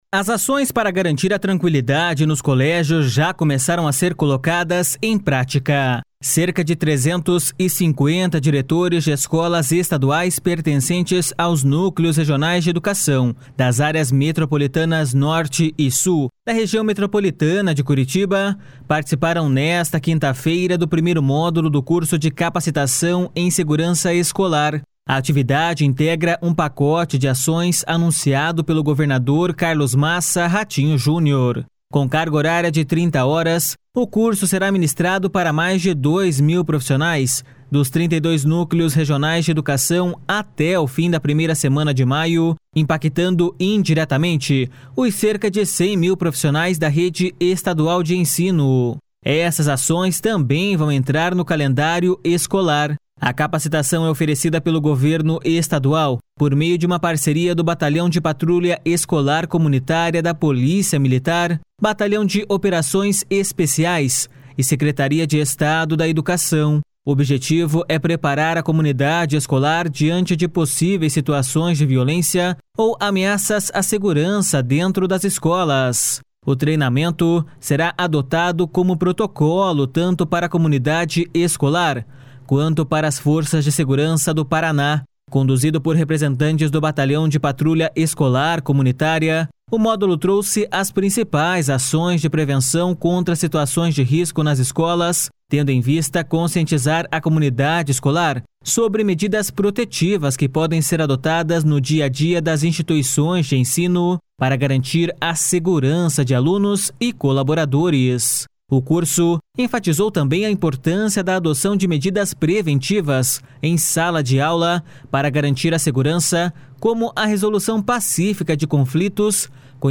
Elas têm foco no aumento da presença das estruturas de segurança pública no ambiente escolar e em uma maior integração entre os órgãos estaduais.// SONORA RATINHO JUNIOR.//